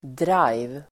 Uttal: [draj:v]